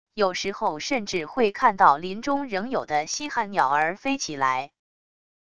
有时候甚至会看到林中仍有的稀罕鸟儿飞起来wav音频生成系统WAV Audio Player